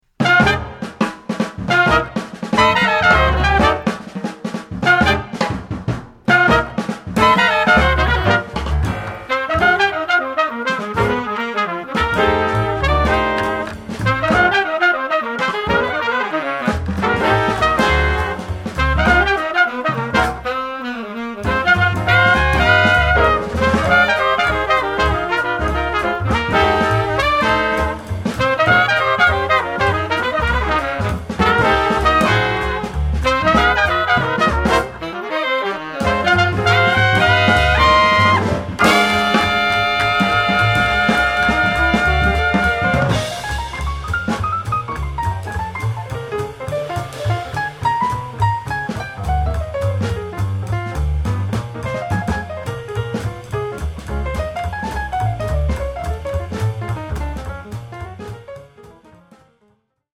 alto & tenor saxophone
piano
bass
drums